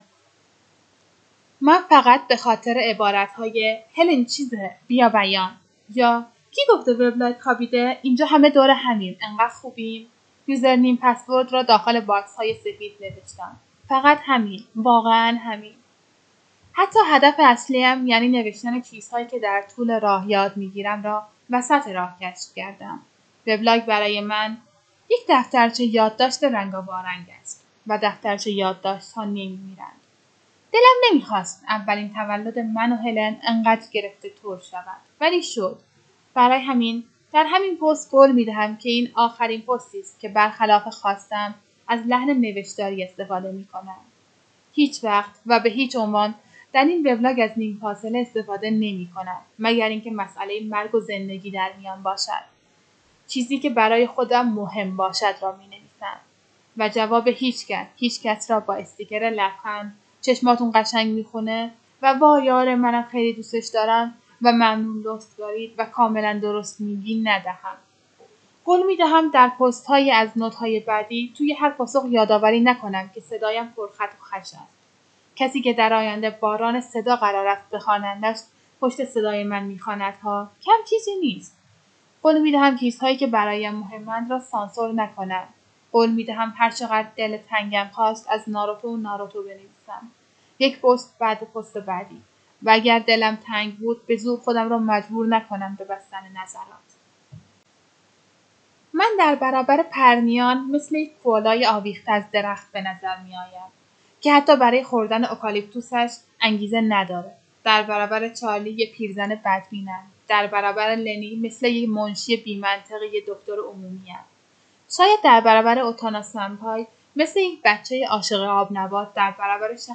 اولا هرکاری کردم نتونستم یه نفس بخونم متنش رو و دو تیکه شد.